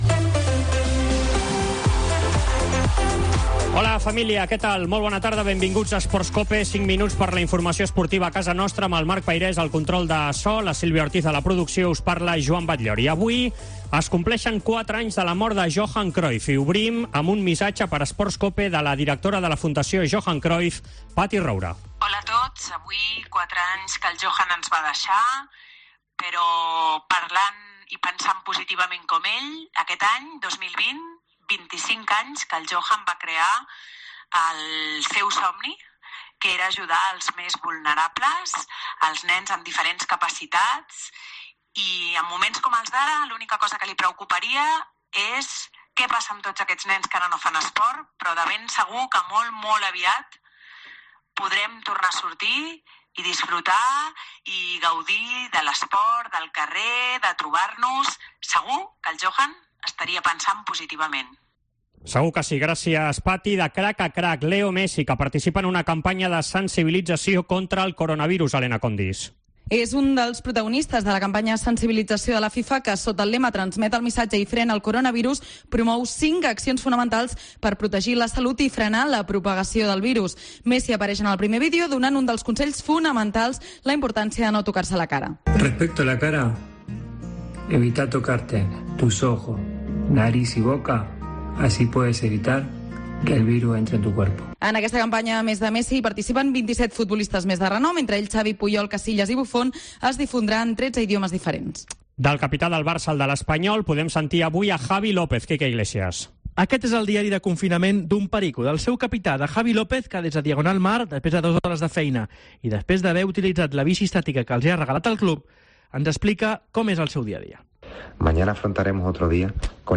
tot l'equip treballant des de casa per portar-vos cinc minuts de la informació a casa nostra.